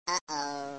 gameError.mp3